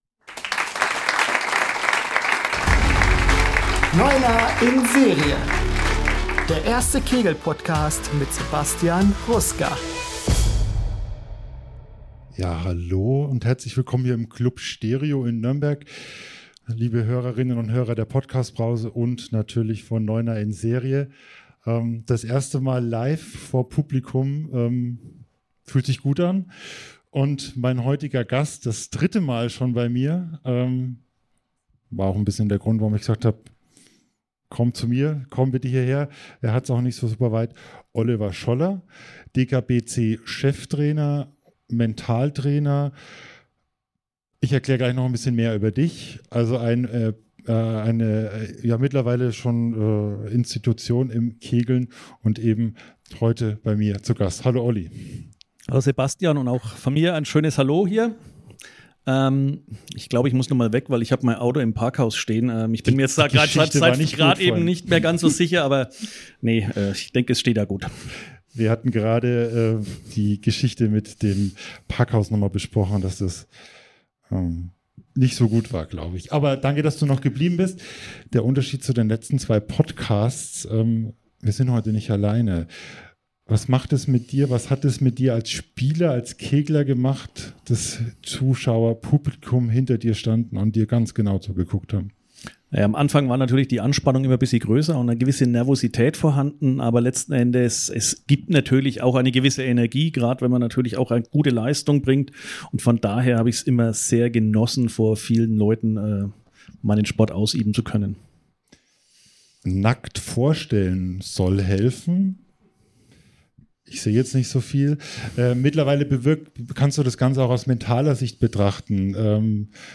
Premiere bei Neuner in Serie: Am 1. April 2026 fand der erste Live-Podcast statt. Neuner in Serie war einer von drei Gäste beim Staffelfinale der Podcast Brause in Nürnberg.